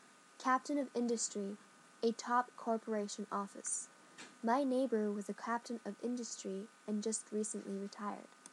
英語ネイティブによる発音は下記のURLから聞くことができます。